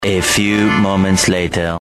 PLAY Money SoundFX